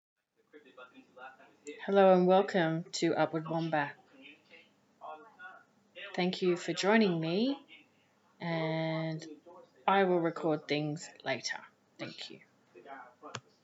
This is the welcome blah that I will change immediately my microphone arrives